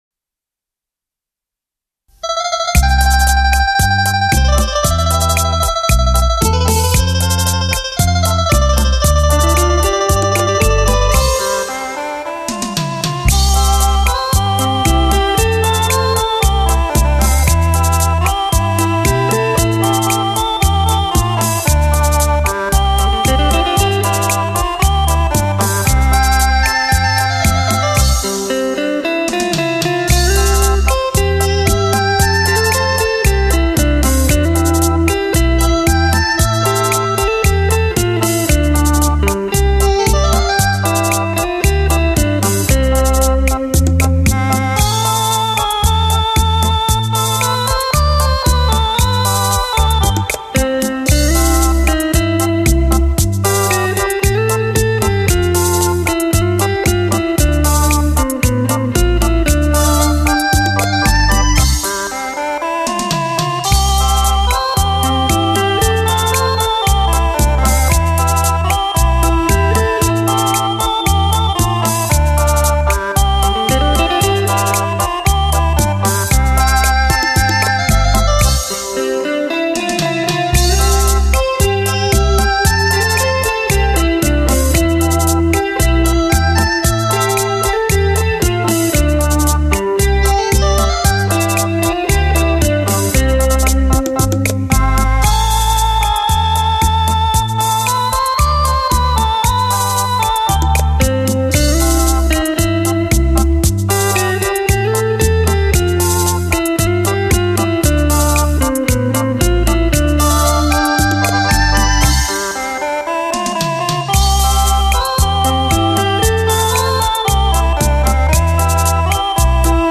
清新典雅的韵味、超凡脱俗的享受，